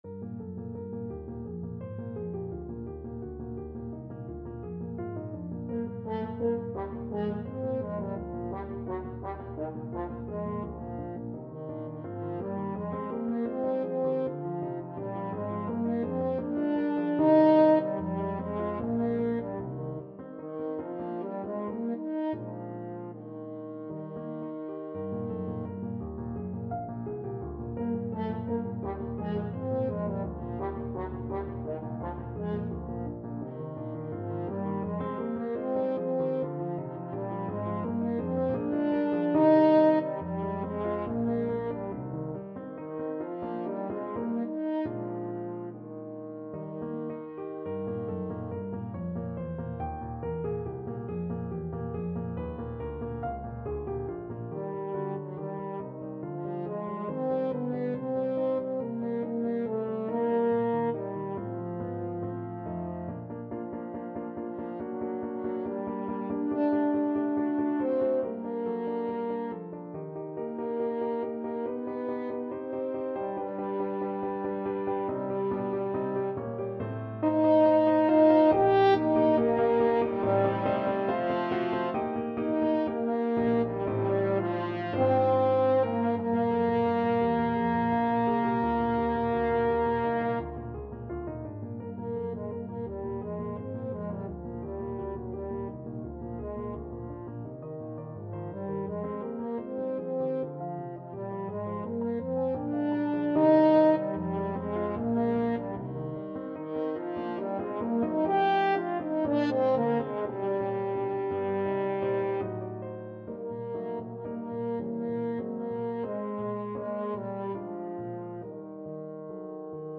Free Sheet music for French Horn
French Horn
Eb major (Sounding Pitch) Bb major (French Horn in F) (View more Eb major Music for French Horn )
4/4 (View more 4/4 Music)
Allegretto = 85 Allegretto
D4-G5
Classical (View more Classical French Horn Music)
elgar_shepherds_song_HN.mp3